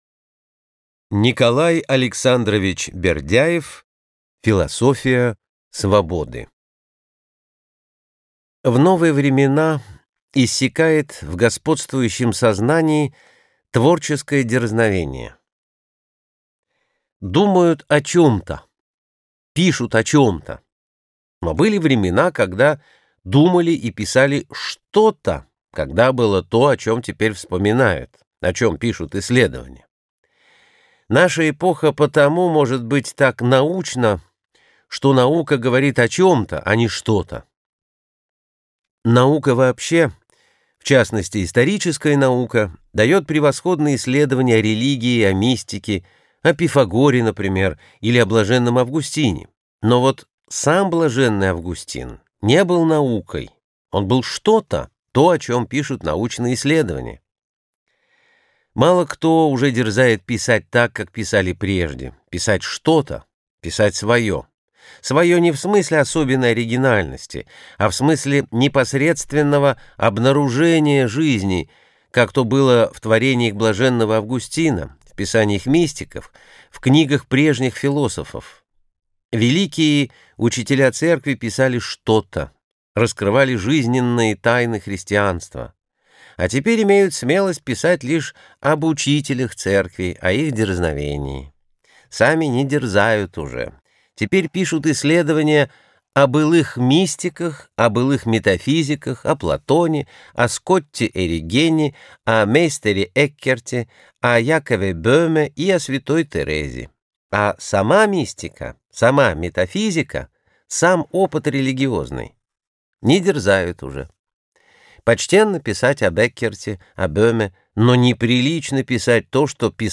Аудиокнига Философия свободы | Библиотека аудиокниг